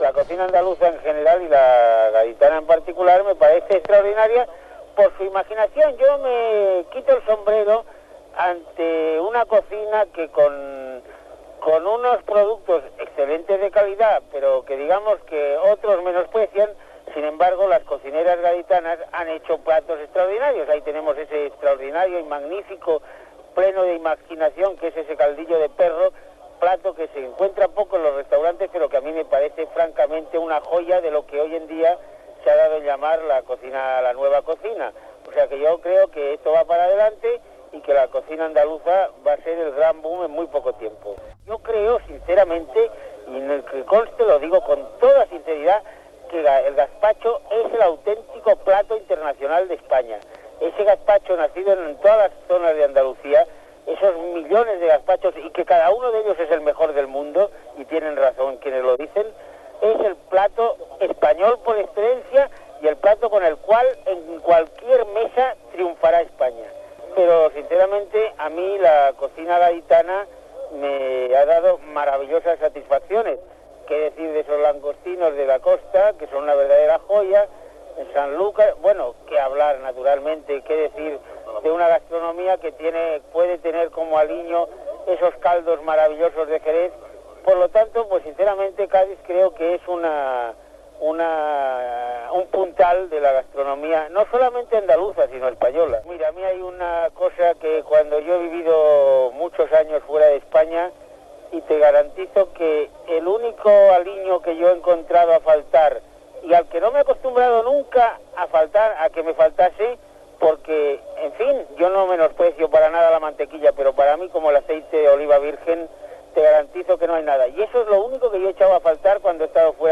parla de la cuina gaditana i del "gaspacho" a la fira Hostel Sur celebrada a Jerez de la Frontera